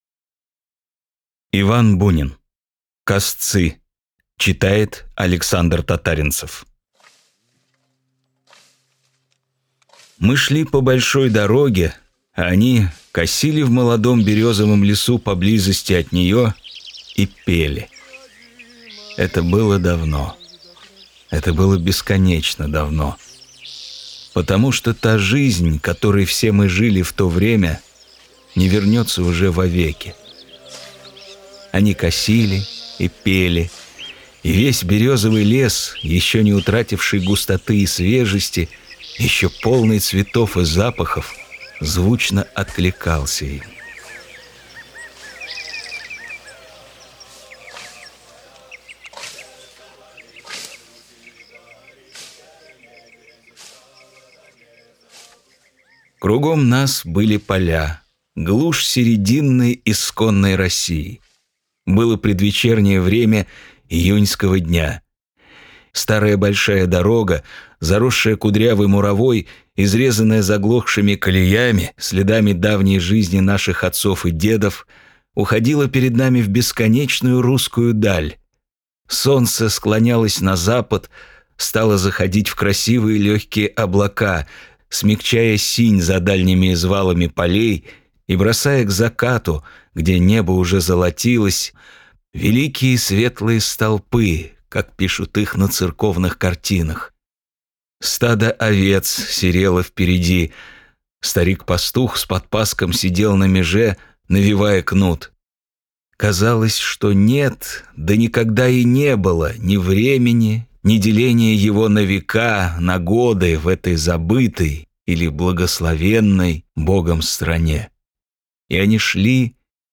Бесплатная аудиокнига «Косцы» от Рексквер.
Классическую литературу в озвучке «Рексквер» легко слушать благодаря профессиональной актерской игре и качественному звуку.